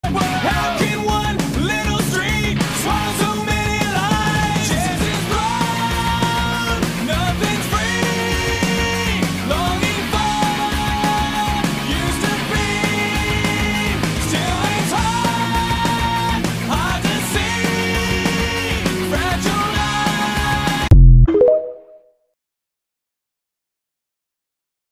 Punk Rock sound effects free download